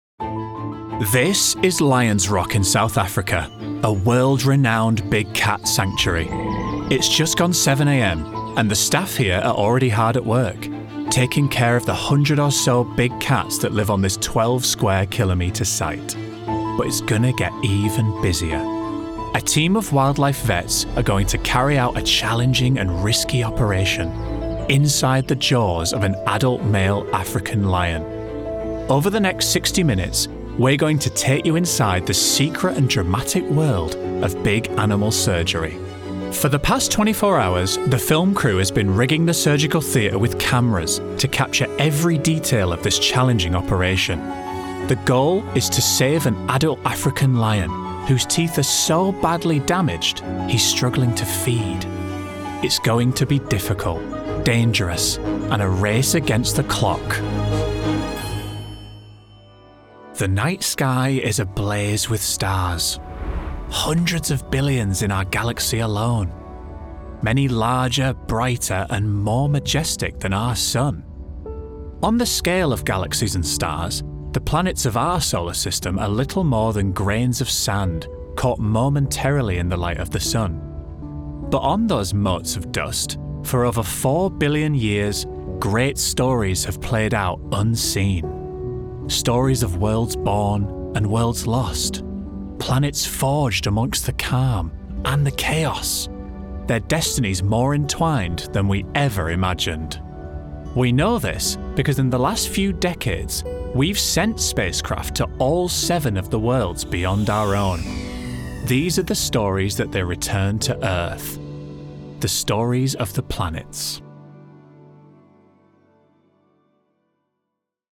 Documentary Showreel
Male
Yorkshire